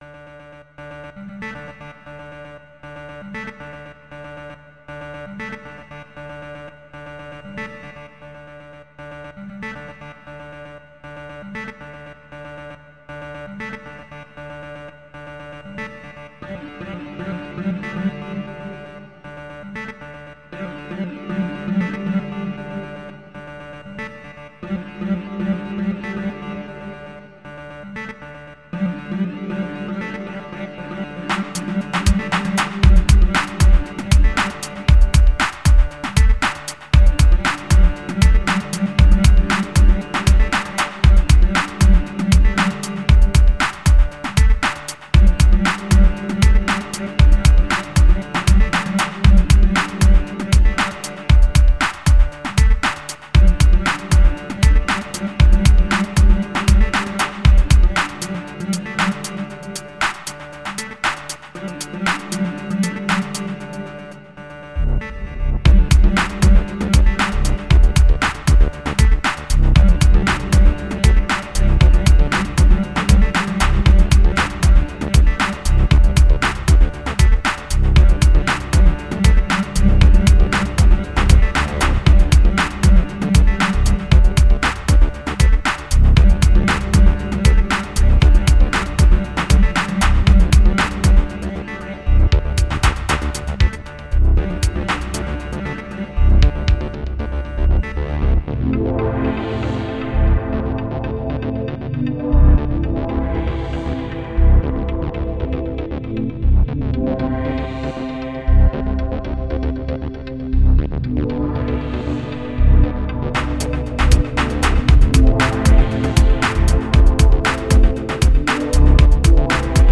(Originally it was some kind of more string like sound, but... well. I got a reputation by now for twisting and tweaking samples upside down.)